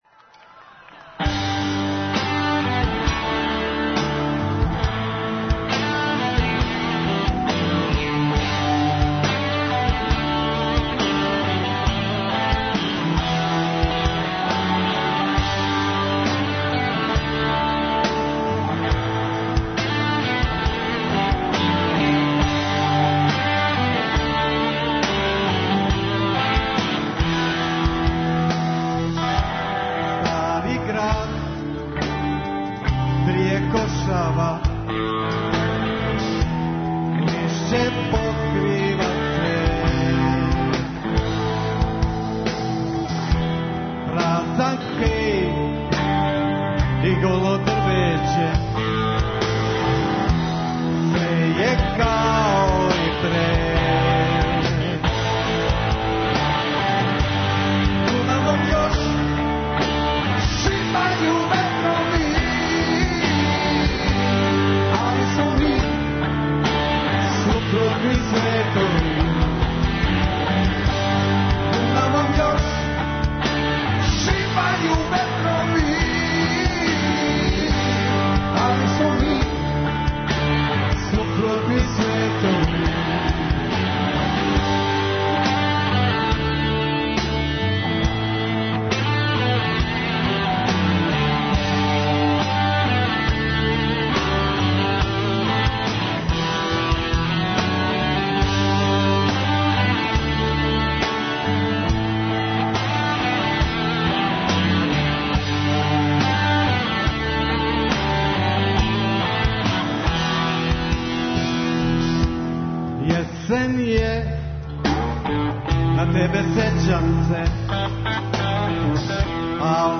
Емитоваћемо јединствен музички материјал, који, захваљујући техничком сектору, чини непроцењиво богатство и идентитет Радио Београда.